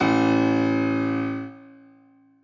b_basspiano_v127l1o2a.ogg